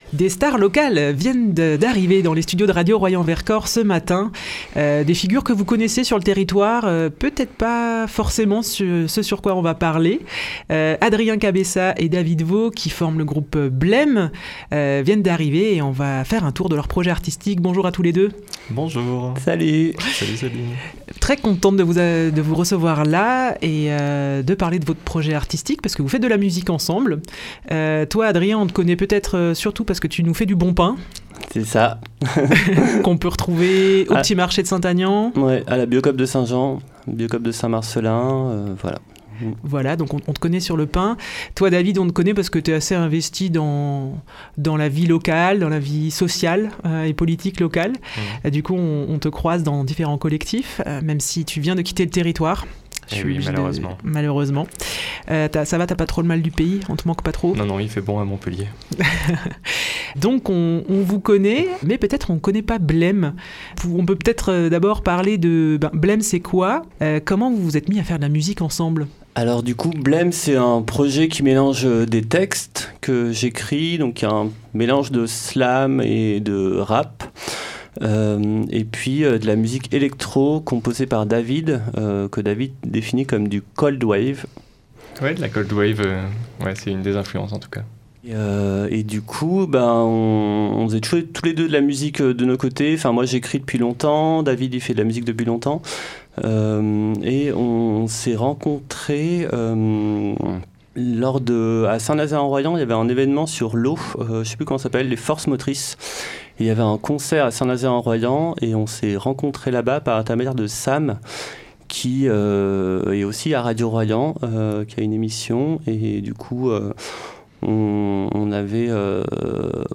Rencontre avec ces deux artistes Saint-Jeannais.